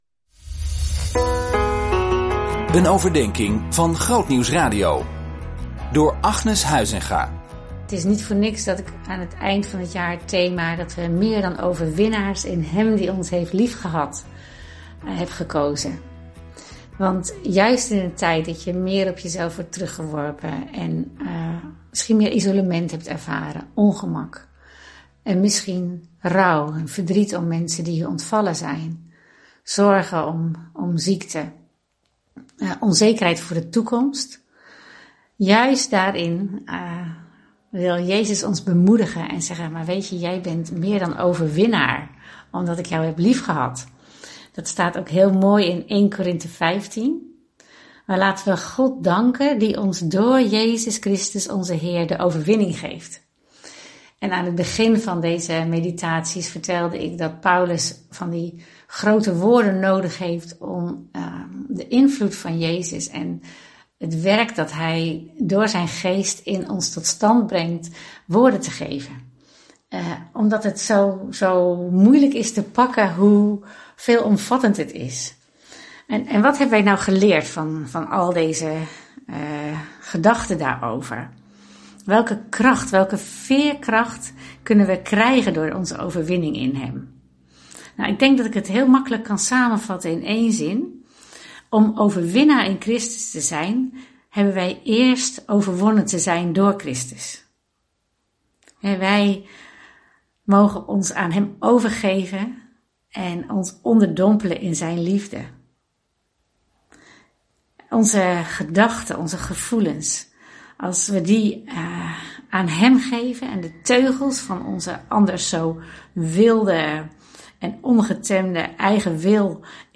Overdenker